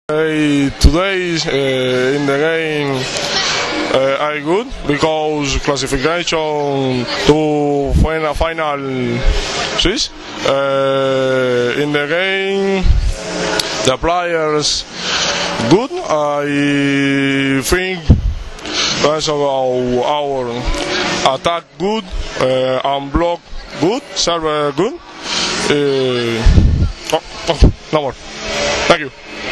IZJAVA VILFREDA LEONA